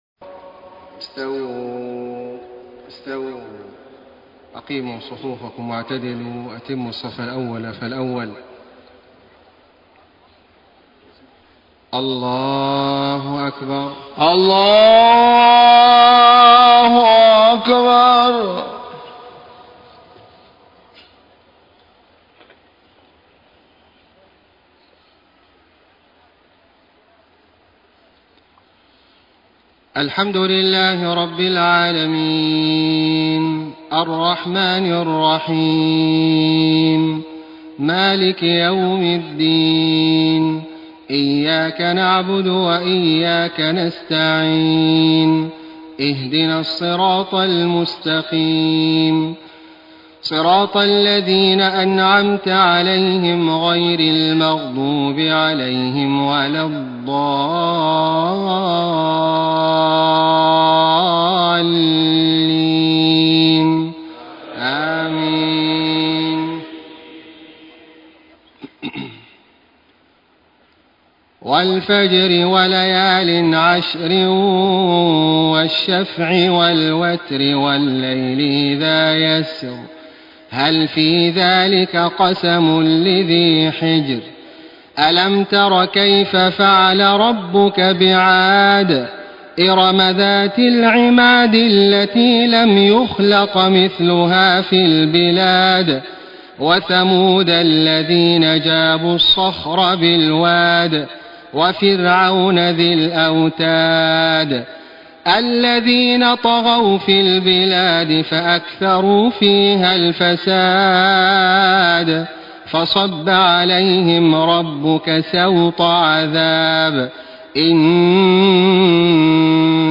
صلاة العشاء 6 - 4 - 1434هـ سورتي الفجر و الضحى > 1434 🕋 > الفروض - تلاوات الحرمين